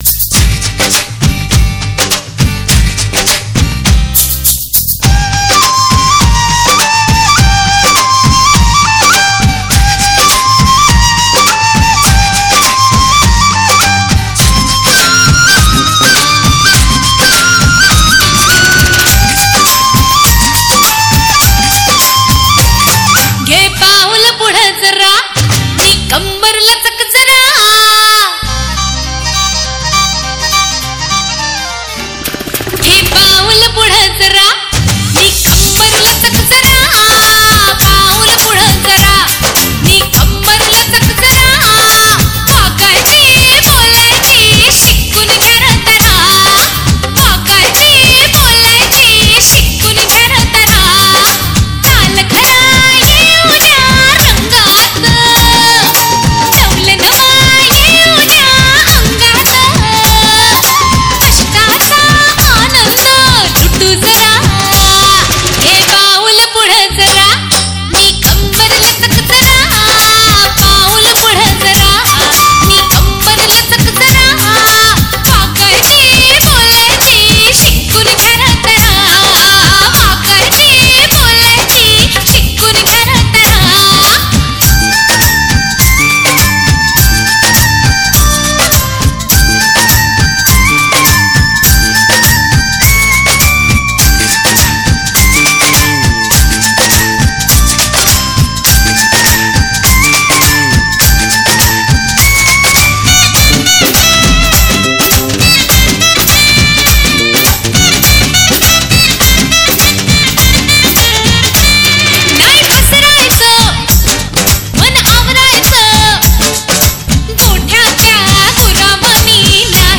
#1 New Marathi Dj Song Album Latest Remix Releases